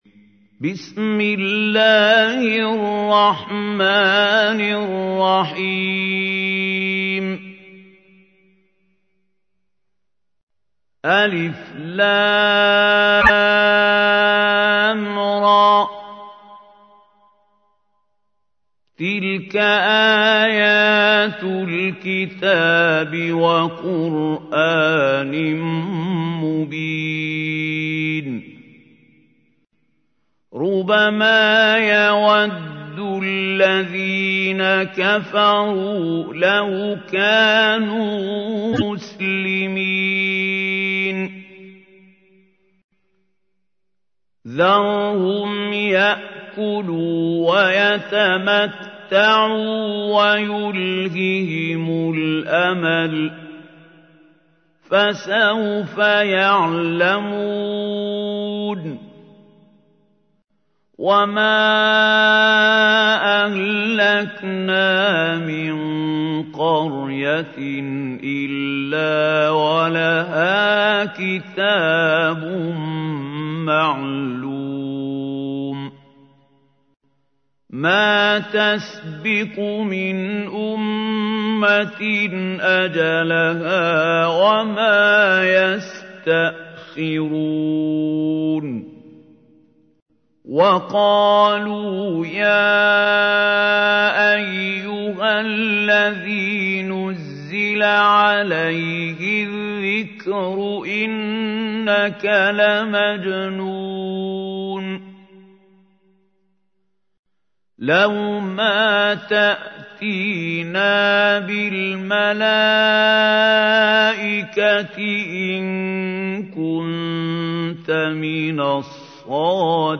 تحميل : 15. سورة الحجر / القارئ محمود خليل الحصري / القرآن الكريم / موقع يا حسين